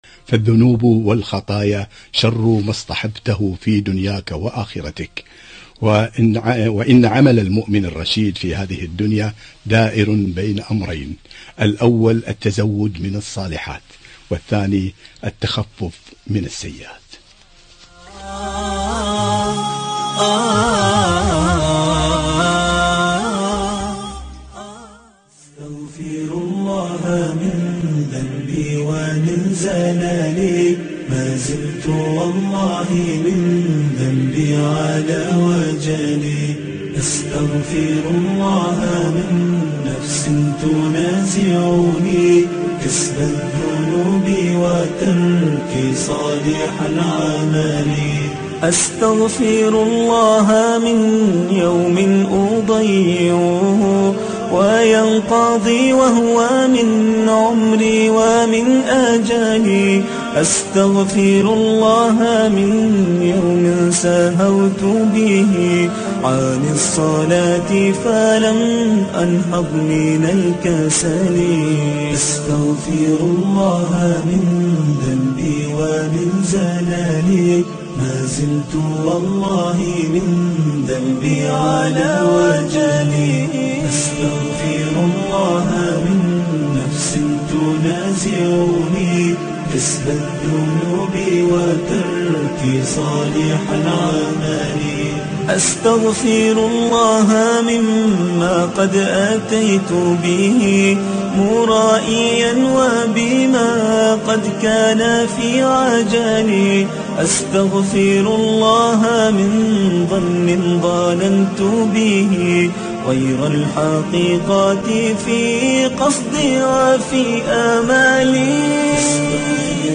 ( إن ربك واسع المغفرة ) - لقاء إذاعي